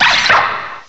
sovereignx/sound/direct_sound_samples/cries/axew.aif at master